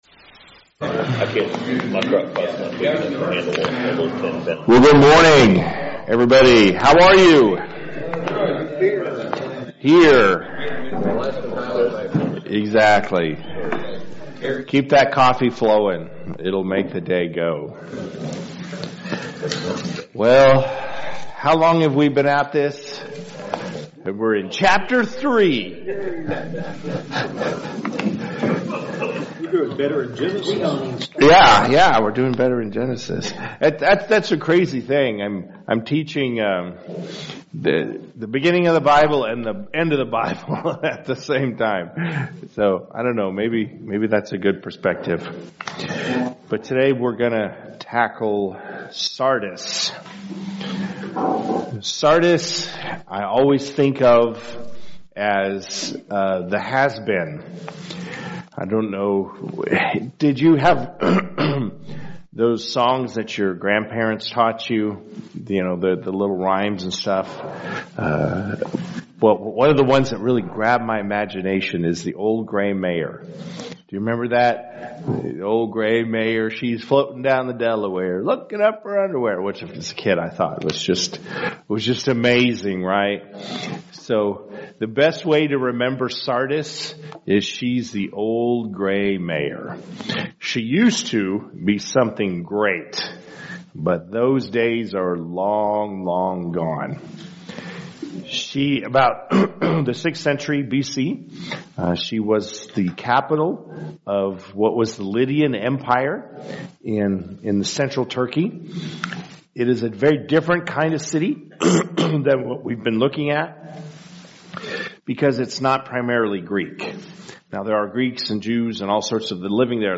Men’s Breakfast Bible Study 3/26/24